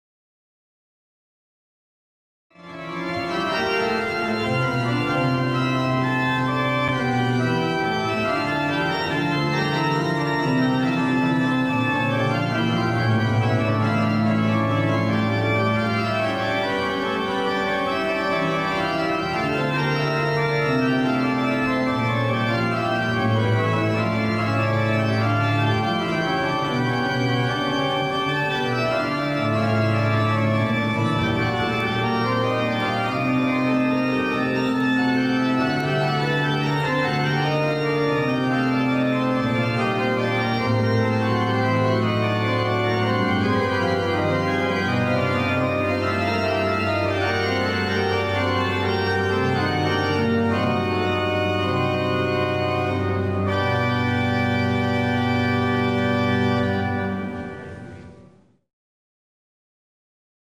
Urkujen
plenoa voitiin kuulla radioinnissa